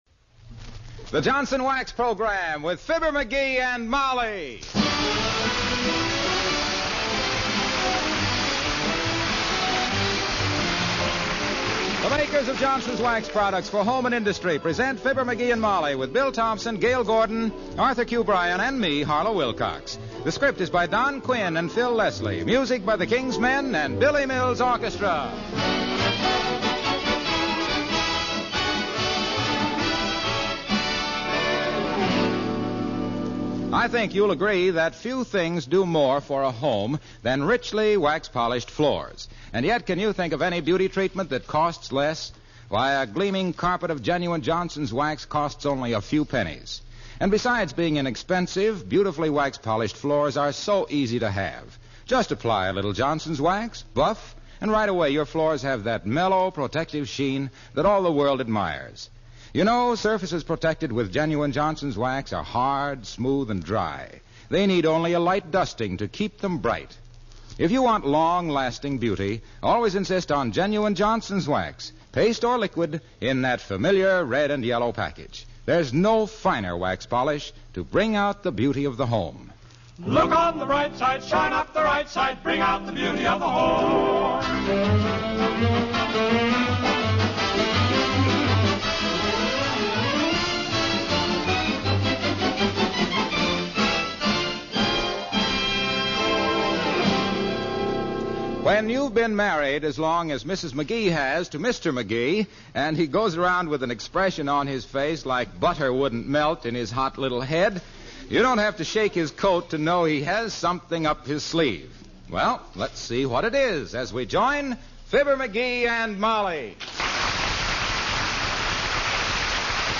Fibber McGee and Molly was an American radio comedy series.